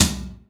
ROOM TOM4A.wav